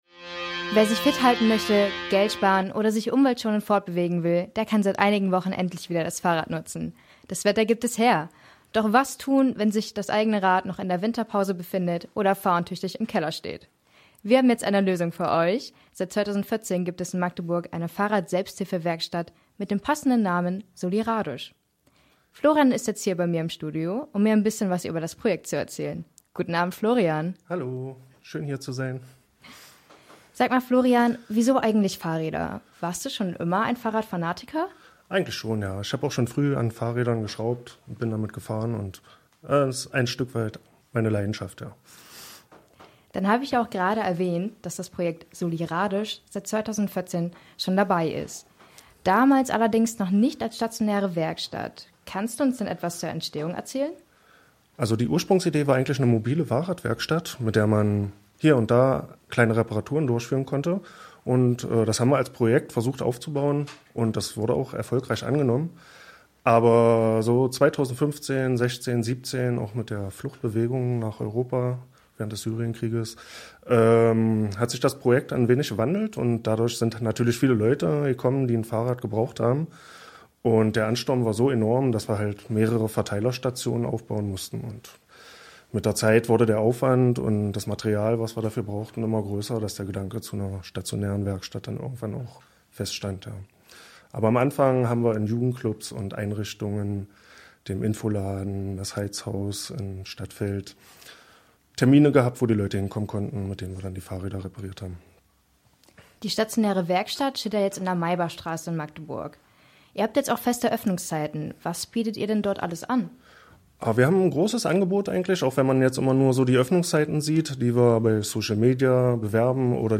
soliRADisch-Interview.mp3